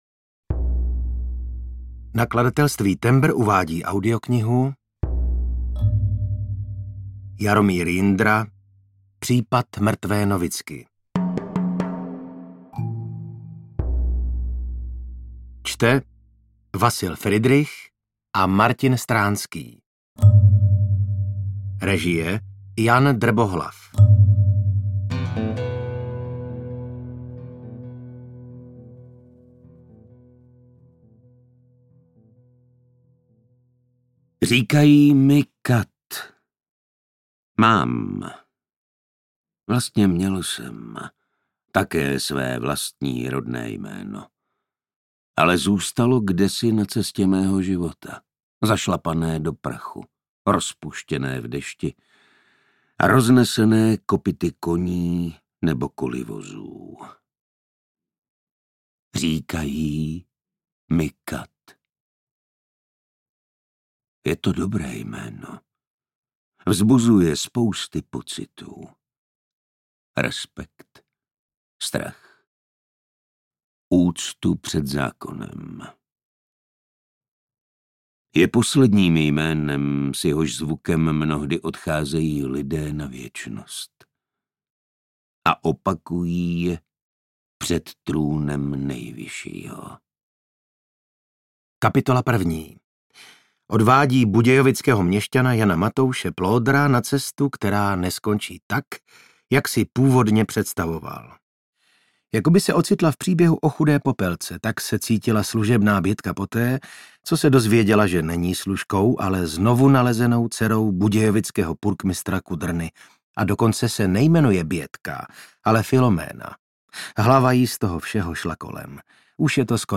UKÁZKA Z KNIHY
Čte: Vasil Fridrich a Martin Stránský
audiokniha_pripad_mrtve_novicky_ukazka.mp3